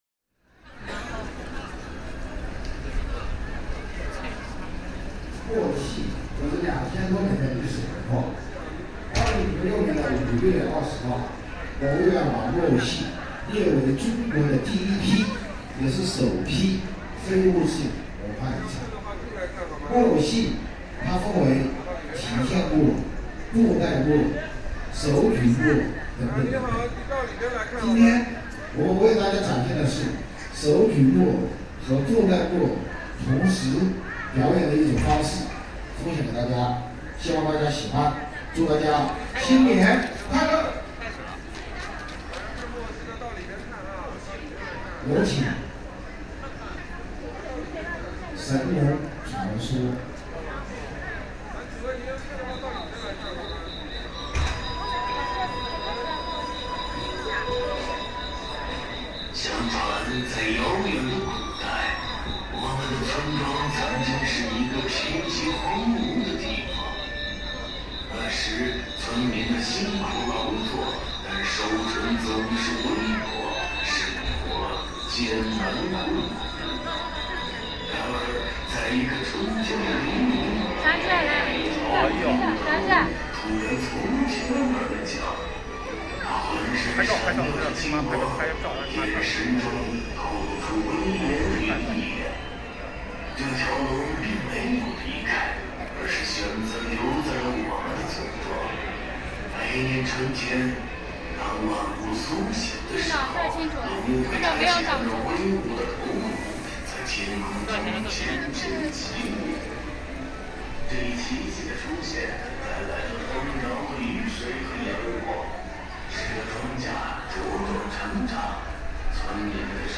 Performance of traditional puppetry
While out shopping, I stumbled upon a performance of puppetry, capturing the scene of people gathered by the roadside to watch the show. This is a traditional form of performance, steeped in history.